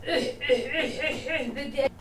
Pretend coughing